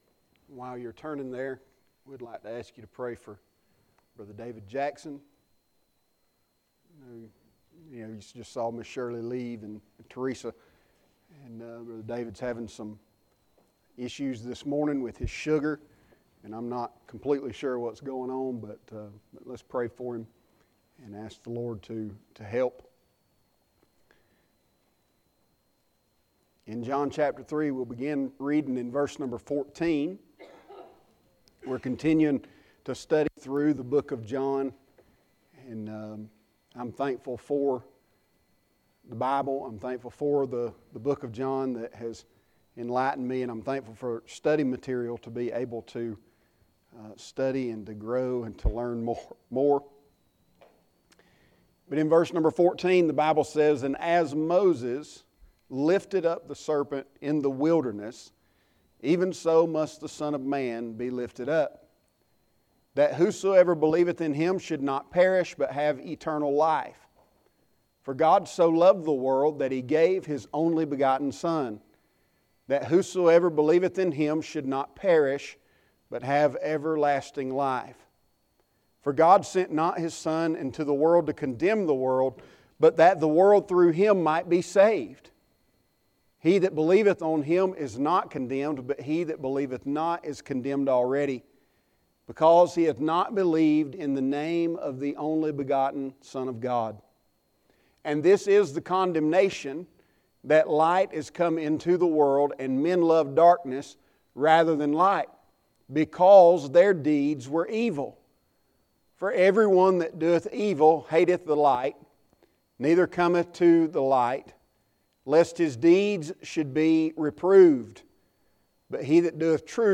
Sermons | Gateway Baptist Church